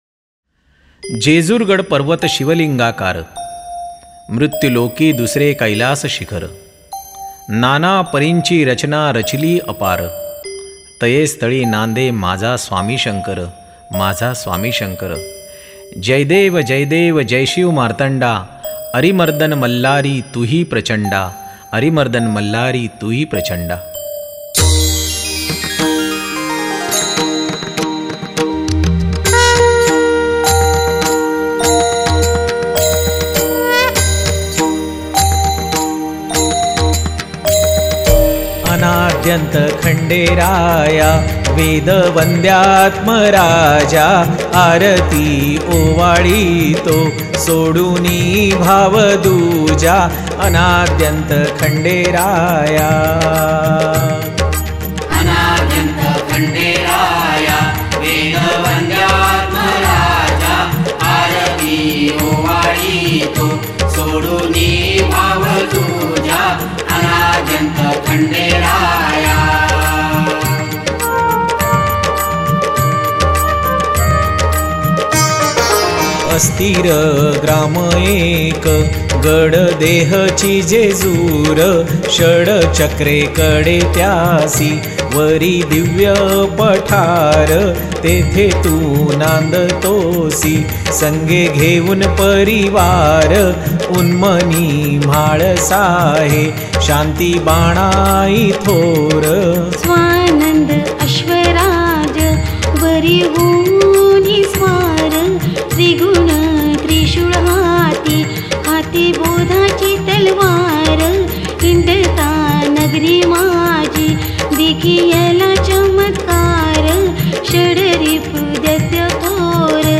गायक
गायीका